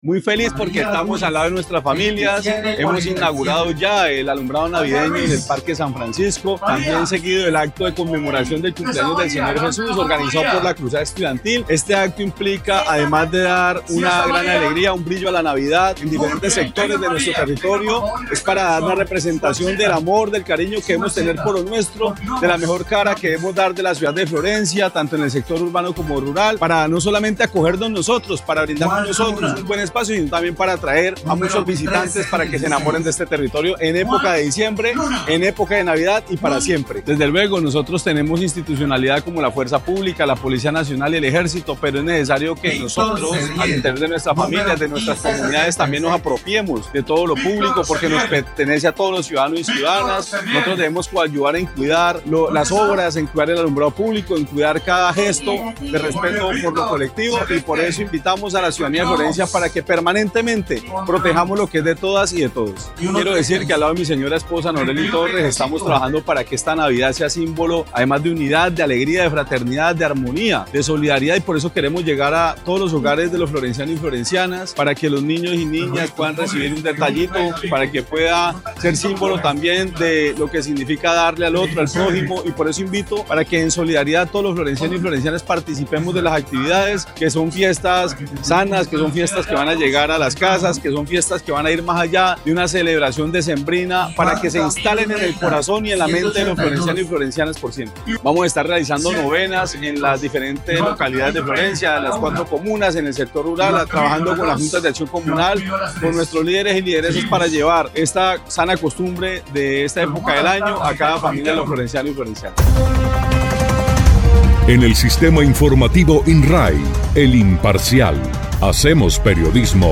El acto se adelantó en la plaza San Francisco.
En medio de la actividad, el alcalde Monsalve Ascanio, dijo que, la Navidad no solo representa alegría, también una oportunidad para mostrar la mejor cara de nuestra ciudad, tanto en el sector urbano como en el rural.
02_ALCALDE_MONSALVE_ASCANIO_ALUMBRADO.mp3